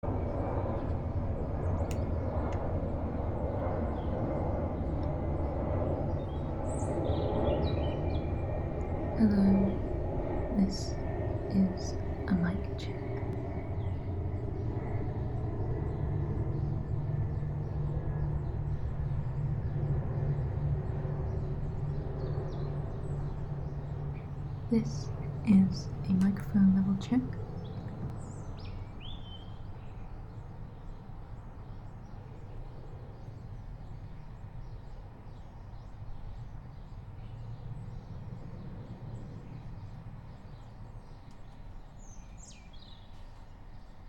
Live from Soundcamp: Radio With Palestine (Audio)